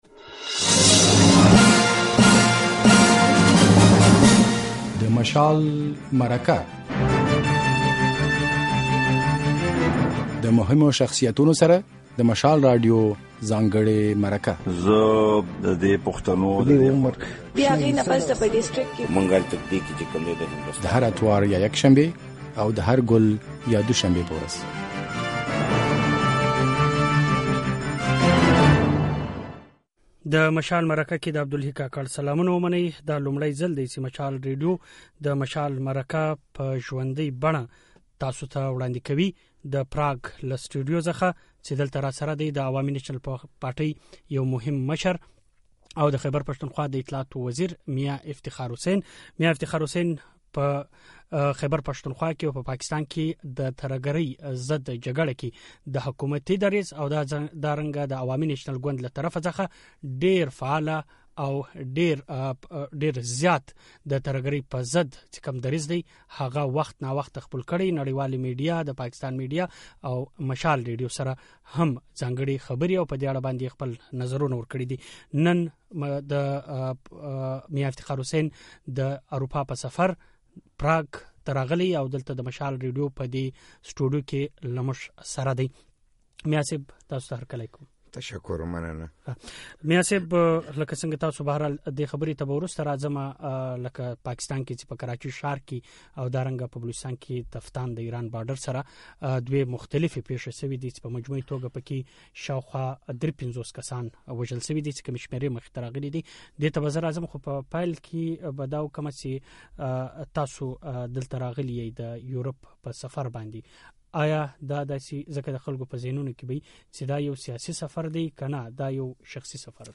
د عوامي نېشنل پارټۍ یو مرکزي مشر او د خېبر پښتونخوا د اطلاعاتو پخوانی وزیر میا افتخار حسېن د مشال مرکه کې له اورېدونکو سره په مخامخ خپرونه کې مېلمه وو.